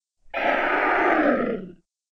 animalworld_monitor.ogg